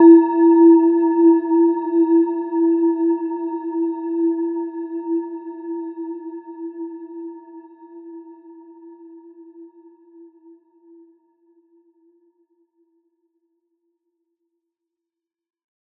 Gentle-Metallic-4-E4-f.wav